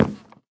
wood1.ogg